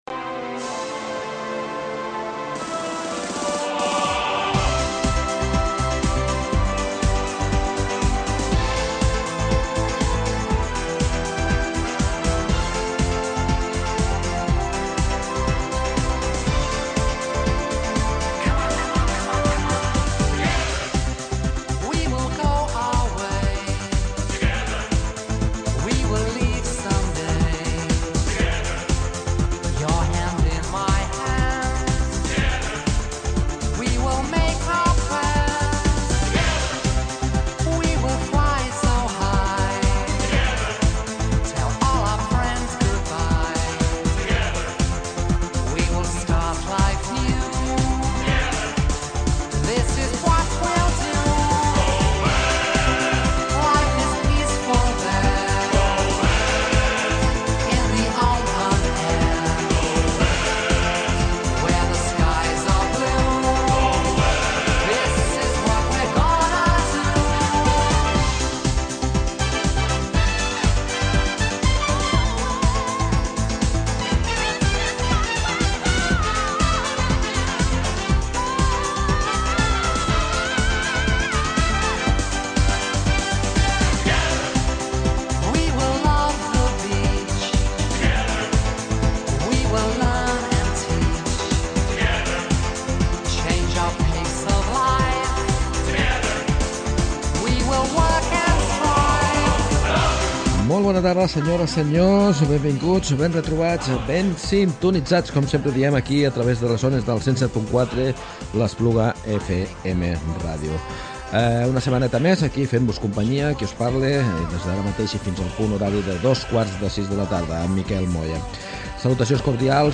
Escoltem les cançons escollides pels oients i t’expliquem alguns dels detalls de les produccions musicals que han marcat a més d’una generació.